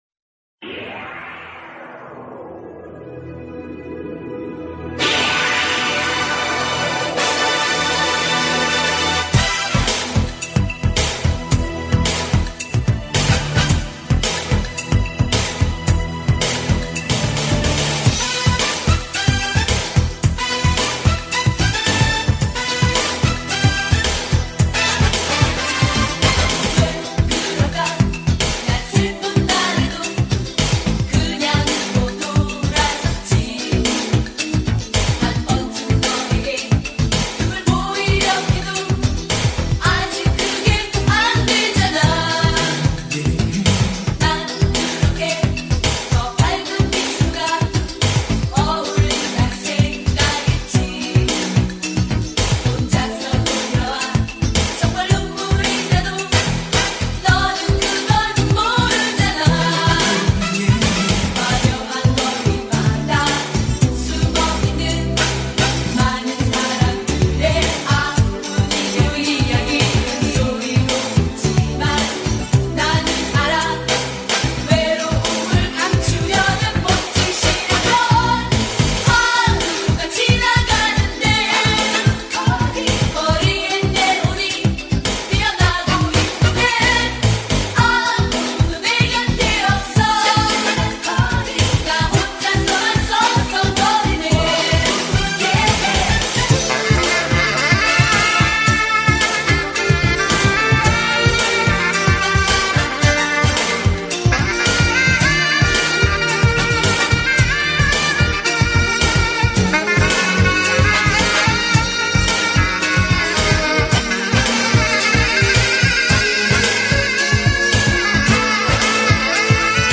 Key Board
Guitar
Bass
Drum
Saxophone
여성 걸그룹